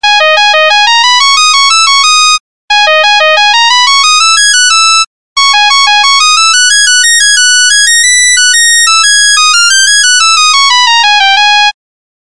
NokiaSamsung рингтоны. Арабские
(народная)